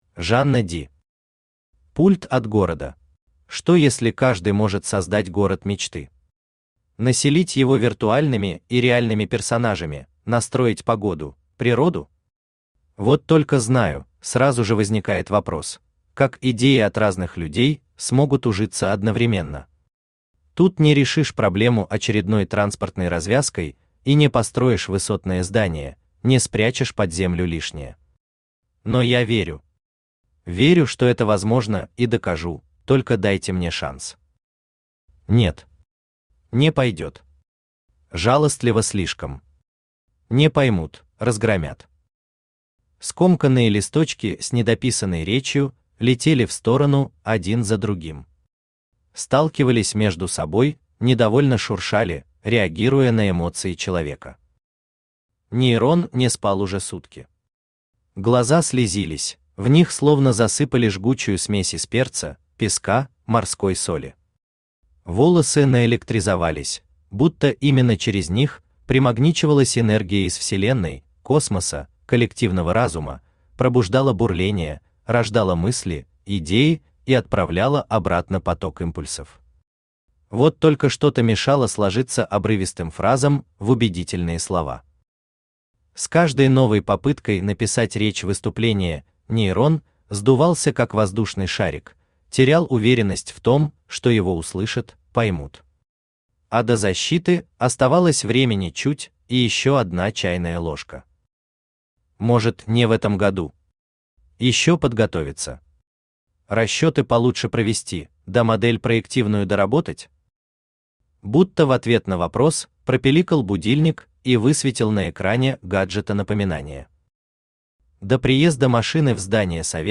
Aудиокнига Пульт от города Автор Жанна Ди Читает аудиокнигу Авточтец ЛитРес. Прослушать и бесплатно скачать фрагмент аудиокниги